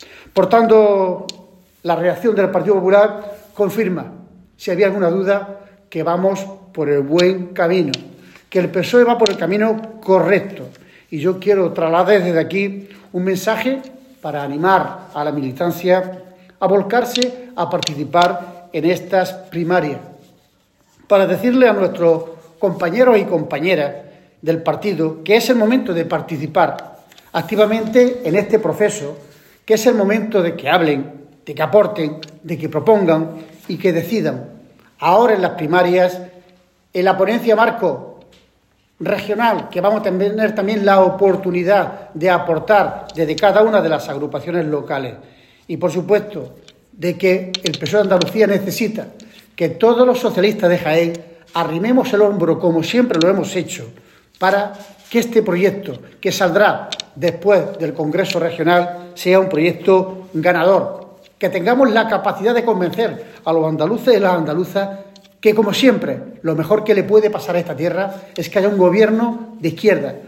En rueda de prensa, Reyes manifestó que “la grandeza de este proceso de democracia interna” del PSOE para elegir a su secretario general “tiene muy desquiciados a los responsables del PP”, puesto que el propio Moreno Bonilla “ha mandado a todos sus voceros a arremeter contra el PSOE”.
Cortes de sonido